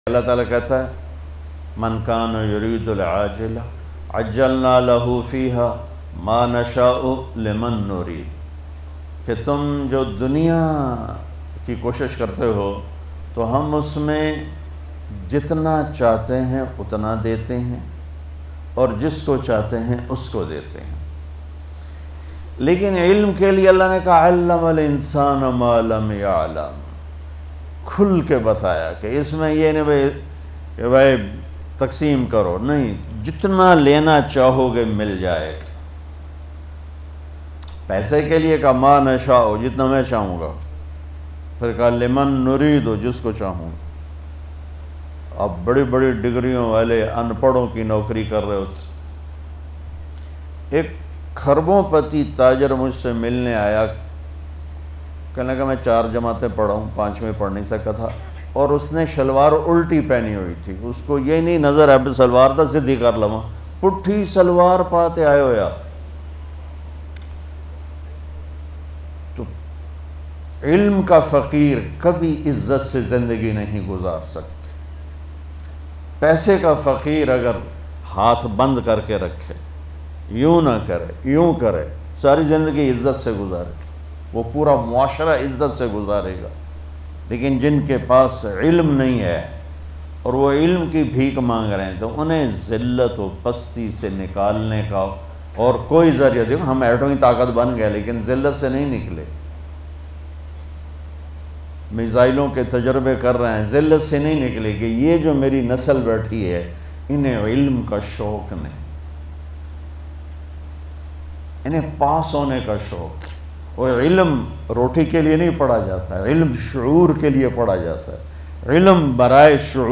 Imp Bayan Molana Tariq Jameel
Tariq Jameel, commonly referred to as Maulana Tariq Jameel, is a Pakistani religious and Islamic scholar, preacher, and public speaker from Tulamba near Mian Channu in Khanewal, Punjab in Faisalabad, Pakistan.